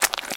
STEPS Swamp, Walk 07.wav